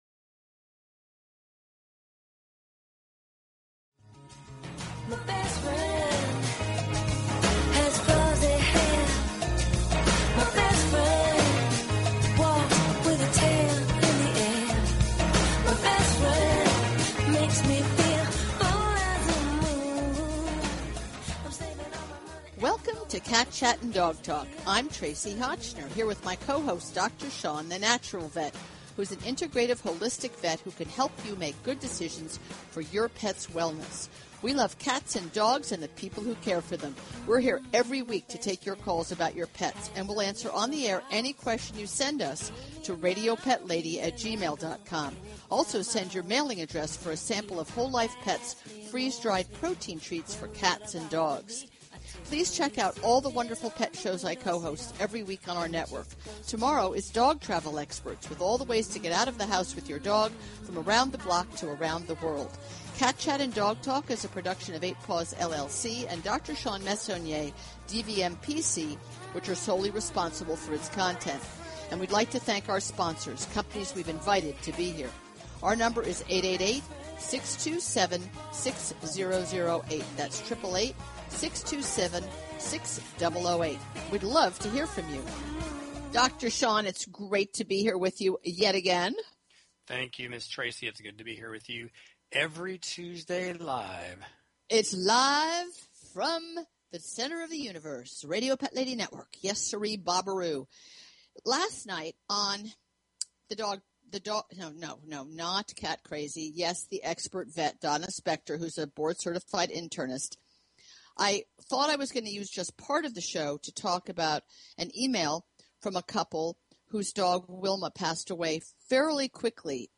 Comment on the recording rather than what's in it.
Now she is here every Tuesday night – Live! - to answer all your dog & cat questions!